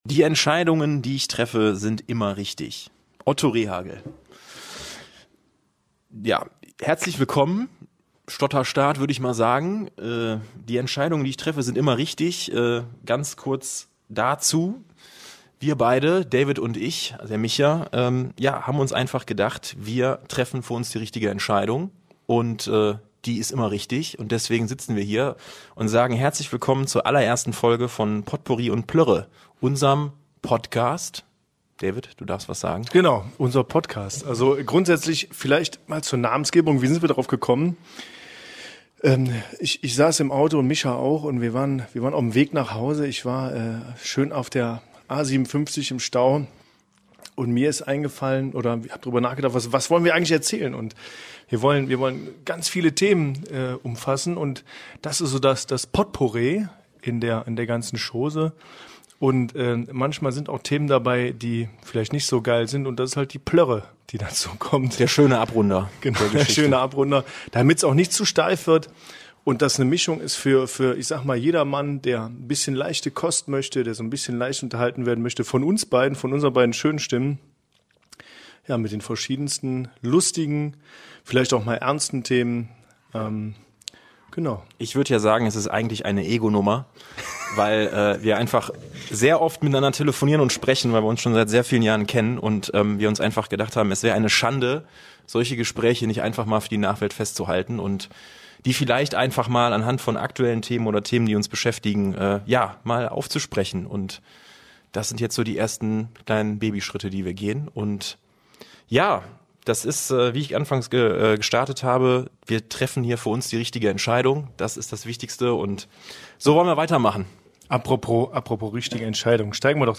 Podcast von zwei Freunden die mehr verbindet als der Job, die gleichen Ziele und auch ein wenig Wahnsinn.